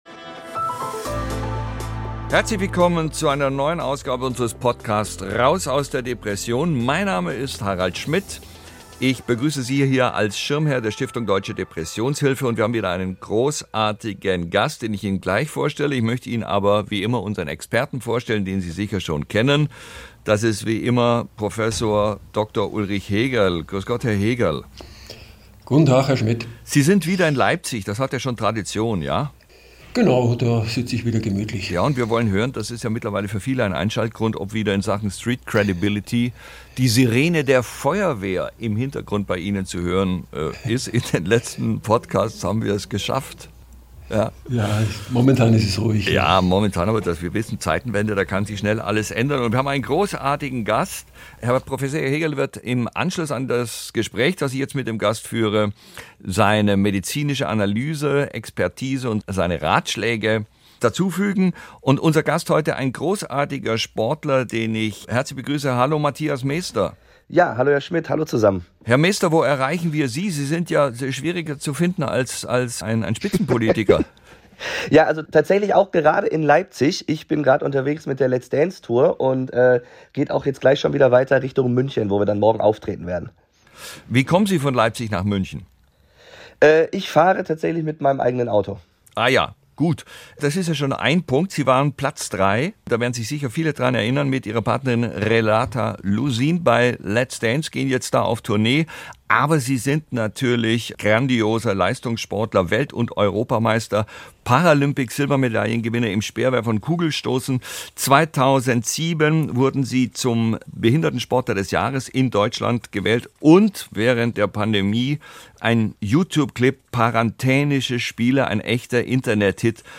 Wie er so einen chronischen Verlauf der Erkrankung verhindert hat und wann er zum ersten Mal offen über seine Depression gesprochen hat, das erzählt der Leistungssportler im Gespräch mit Harald Schmidt, Schirmherr der Deutschen Depressionshilfe. Kann der Druck, den eine solche Karriere im Leistungssport mit sich bringt, ein Auslöser für eine Depression sein?